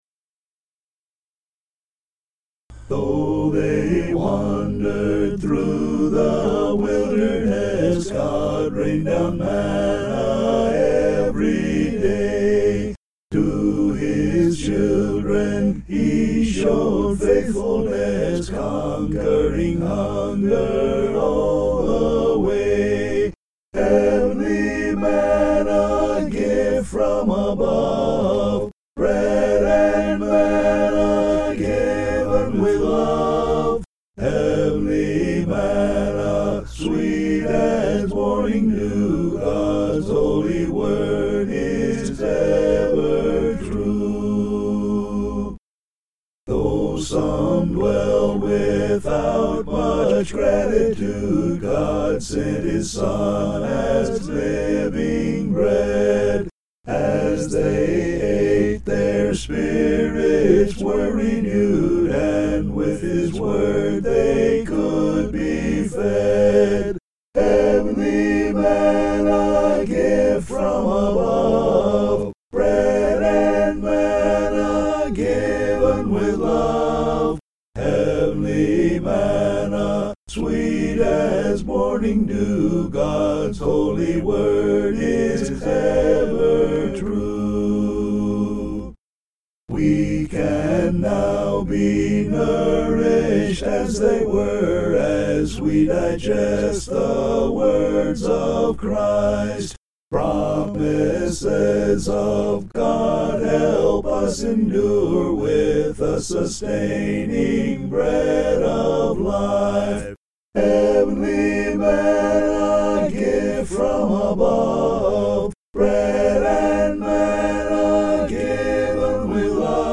(An original hymn)